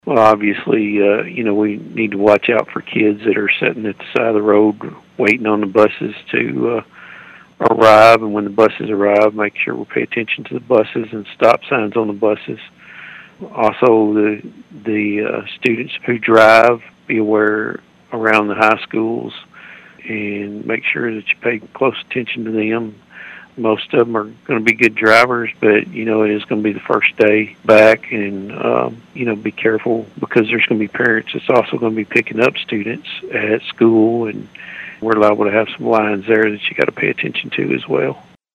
Sheriff Karl Jackson offered some tips to help keep the school year safe and accident free.(AUDIO)